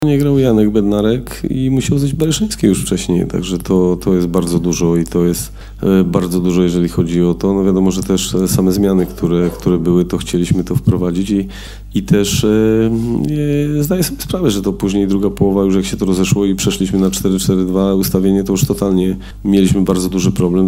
O tym z czego wynikały problemy reprezentacji Polski w drugiej części starcia, po meczu mówił selekcjoner- Michał Probierz.